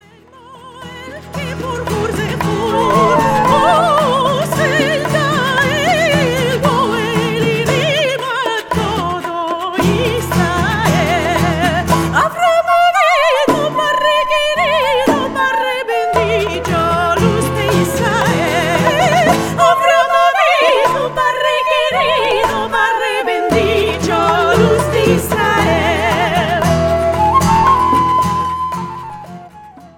Featuring studio recordings of folk
Folk